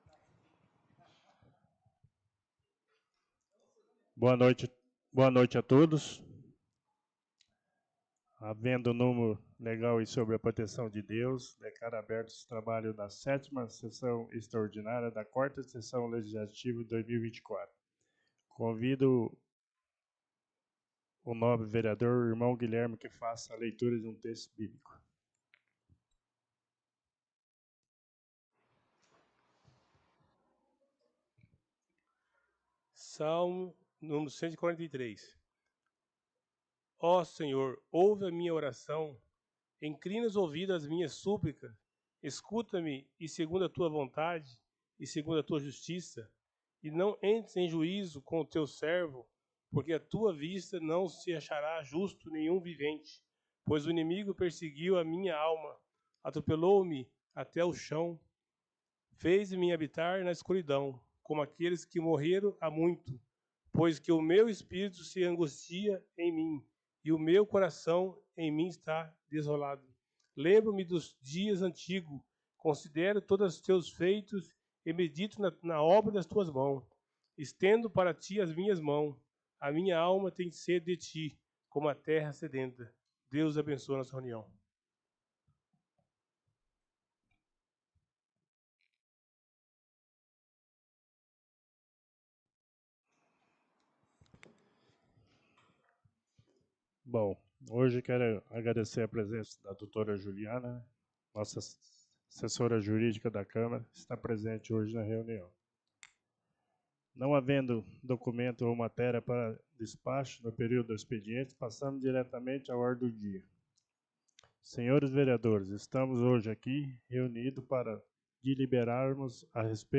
7ª Sessão Extraordinária 03-04-24.mp3